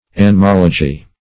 Anemology \An`e*mol"o*gy\, n. [Gr.